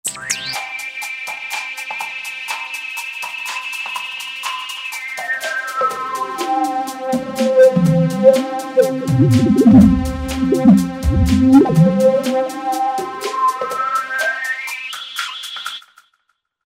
008 123 ColdBuzz Very nice aftertouch/mod. wheel filtering action on this one